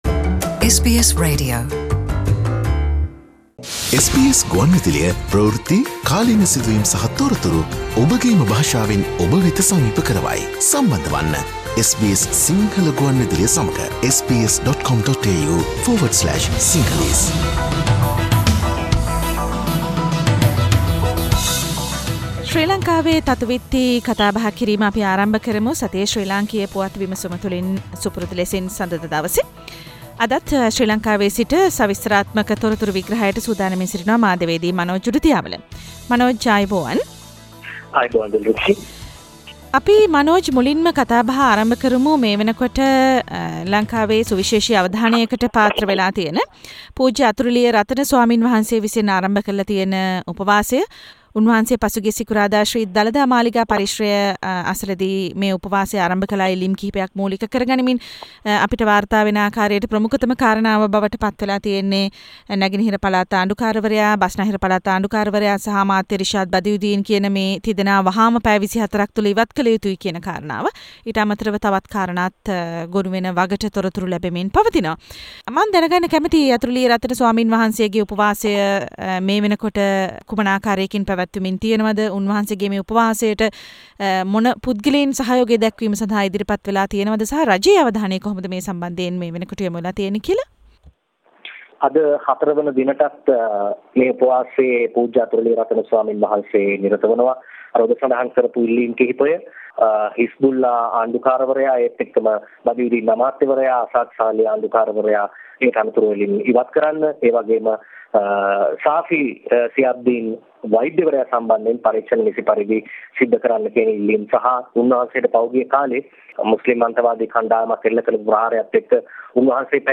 සතියේ දේශපාලන පුවත් සමාලෝචනය